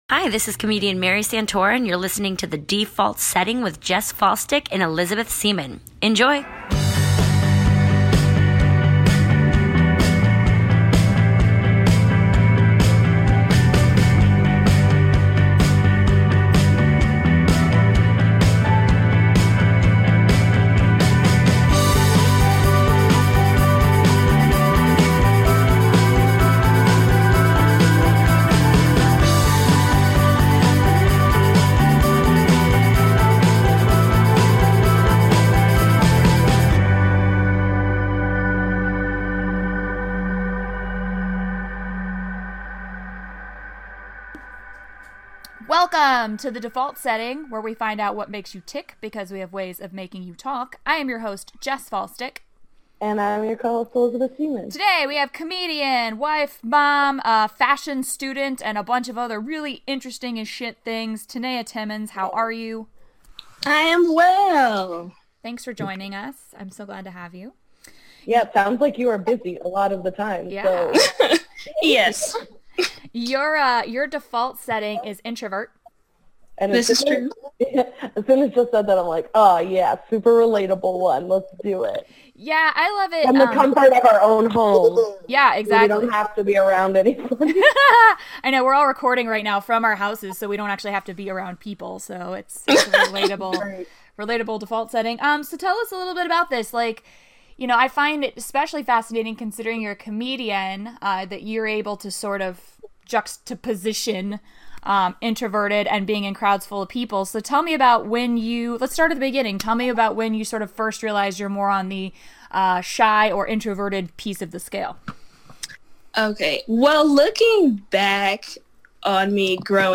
Apologies for the muffled audio.